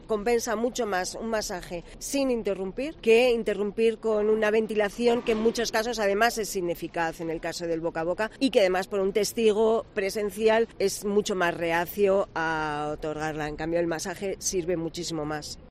Un médico del Samur nos explica paso a paso lo que debemos hacer para llevar a cabo una RCP